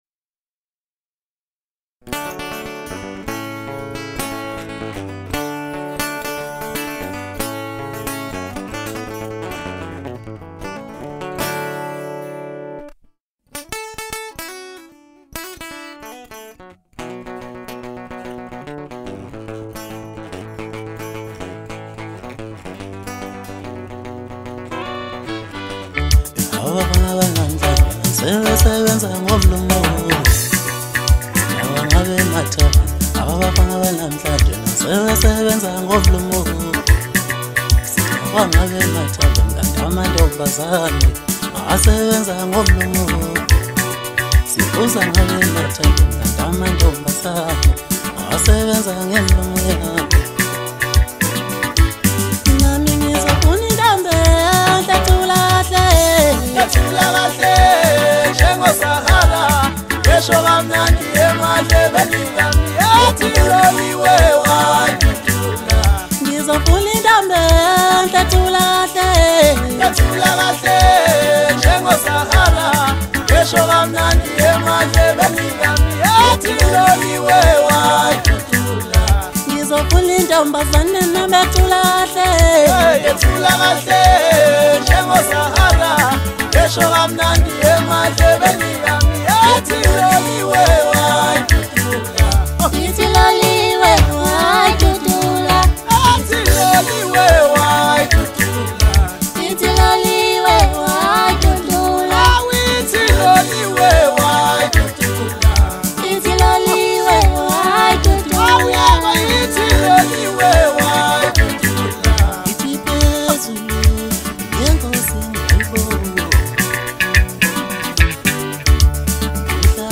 Home » Maskandi
South African singer-songwriter